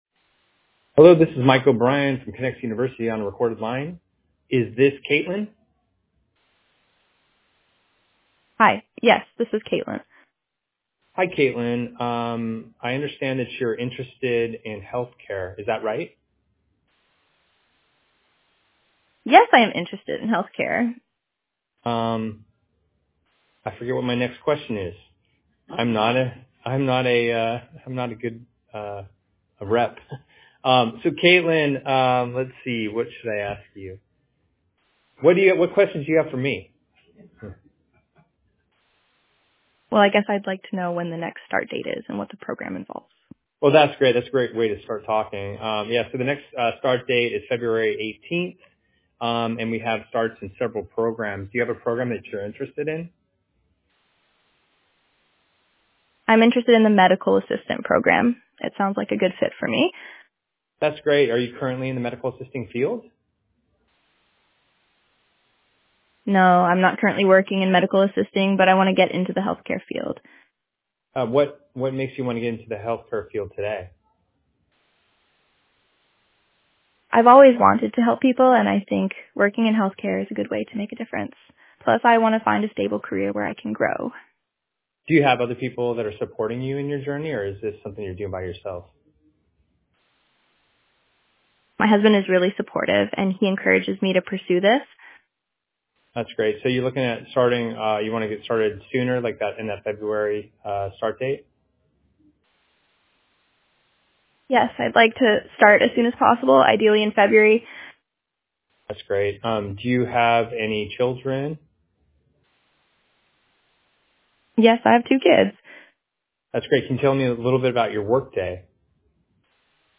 Reps Role Play With Voice AI